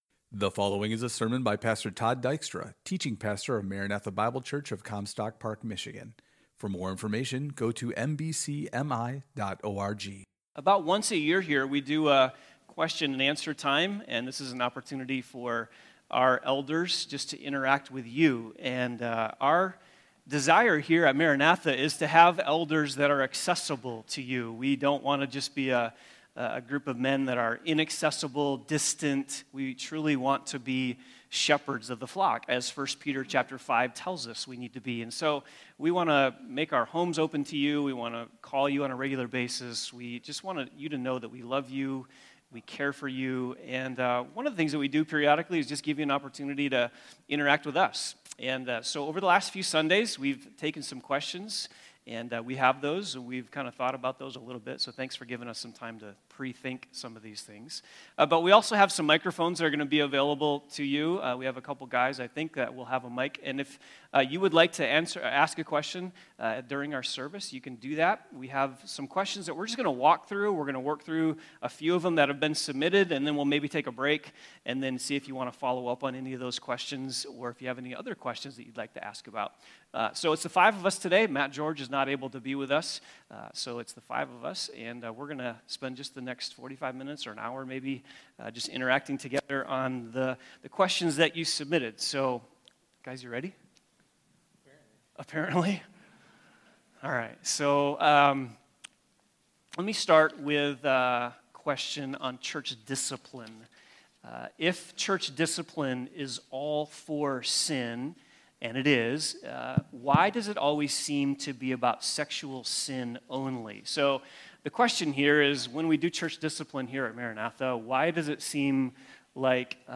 Elders’ Q & A